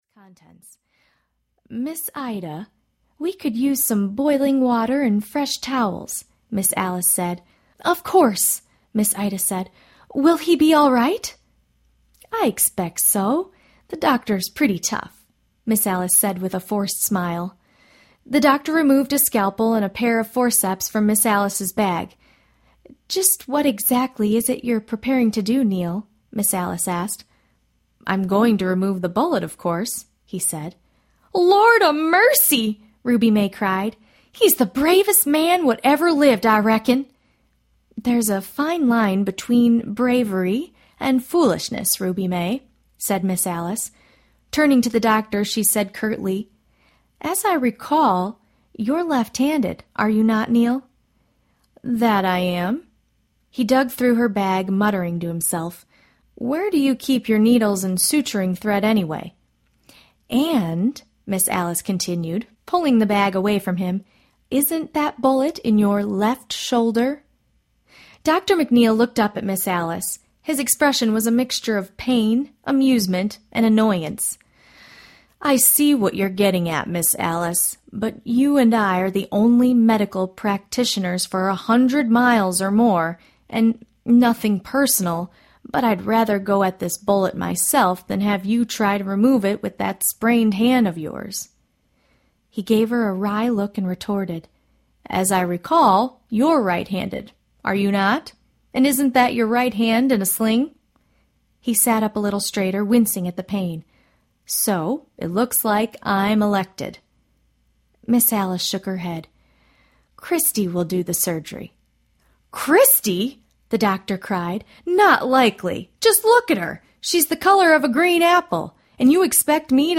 Midnight Rescue Audiobook
2.4 Hrs. – Unabridged